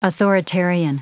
Prononciation du mot authoritarian en anglais (fichier audio)
Prononciation du mot : authoritarian